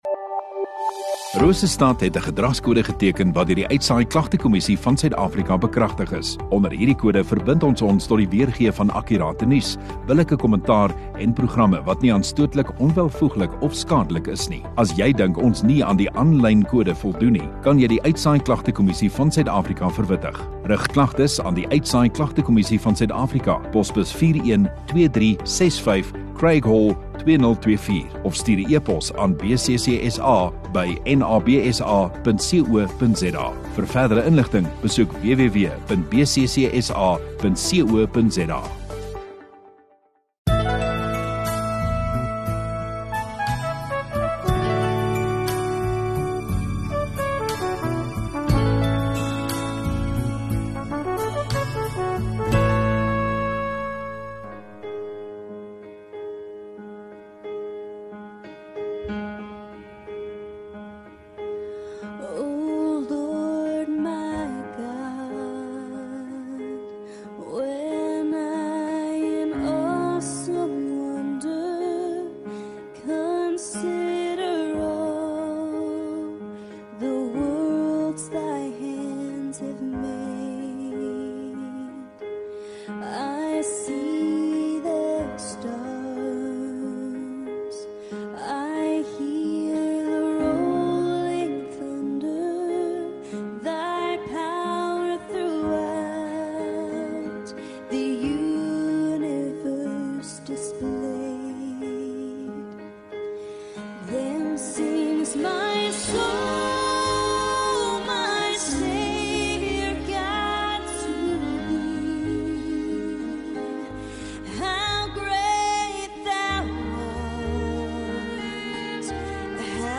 16 Aug Saterdag Oggenddiens